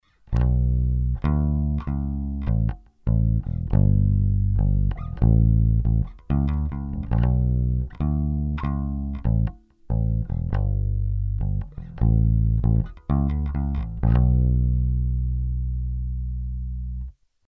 Треки были записаны напрямую в линейный вход звуковой карточки без какой либо текущей и последующей обработки звука.
New bass with mid cut
Вышеприведенные треки доработанного баса с онборд преампом. 1-й трек записан - при вырезанных средних частотах (т.е. согласно пиведенной в начале схеме при замкнутом переключателе S1); 2-й трек - без выреза частот (S1 разомкнут соот.)
new_bass_with_mid_cut.mp3